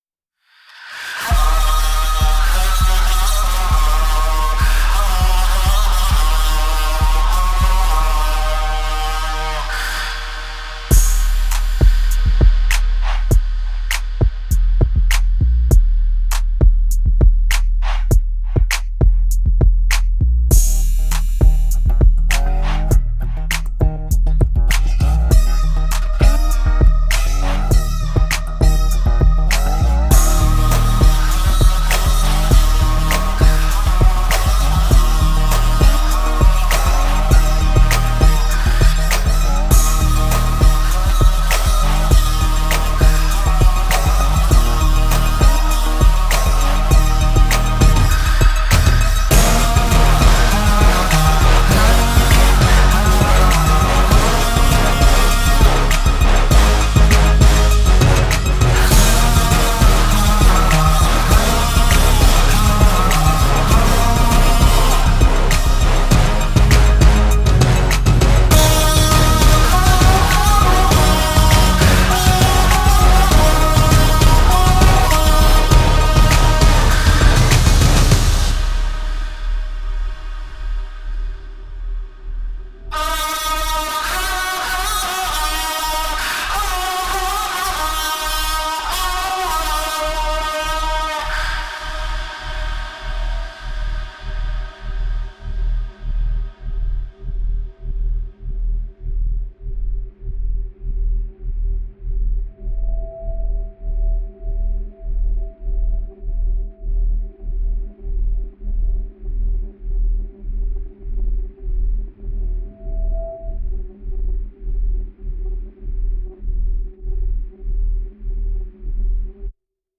[电影原声]